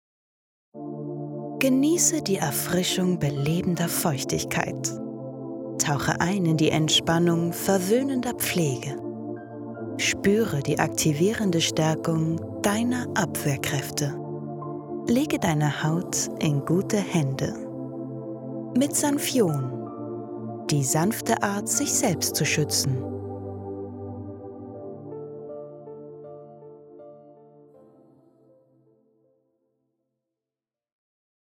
Ihre Stimme ist freundlich, sachlich/kompetent, über erzählerisch, aber auch jung, werblich, lieblich oder verführerisch.
freundlich, warm, wandelbar, frech und verführerisch
Sprechprobe: Sonstiges (Muttersprache):
Webung_Sanfion.mp3